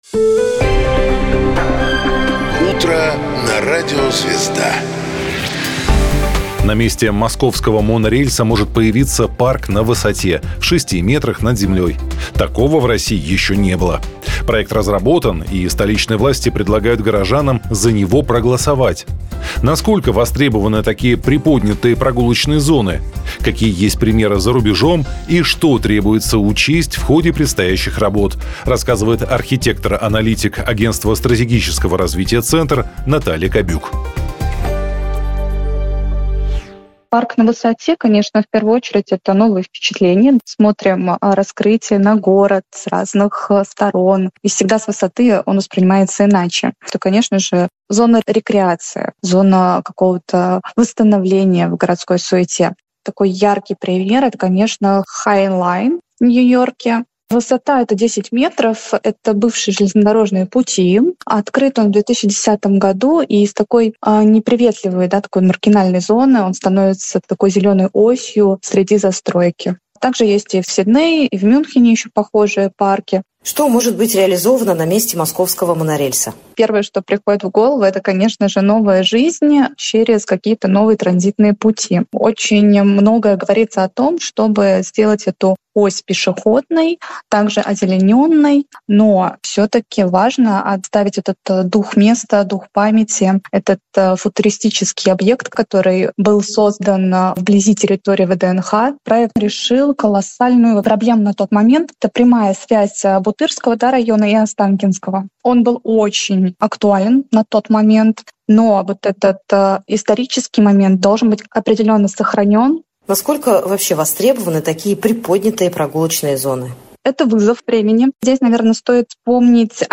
Сюжет радио Звезда Парк на месте монорельса.mp3